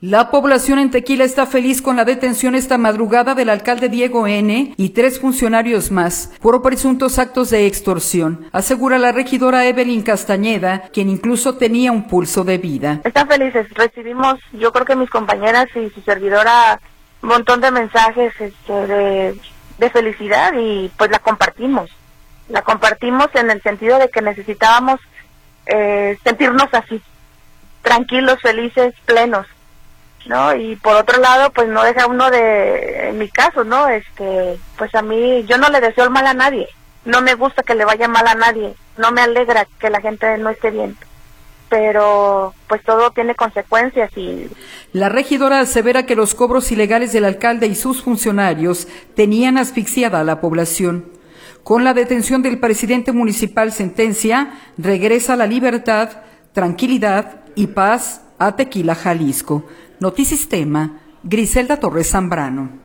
En Tequila la población aplaude la captura del alcalde: Regidora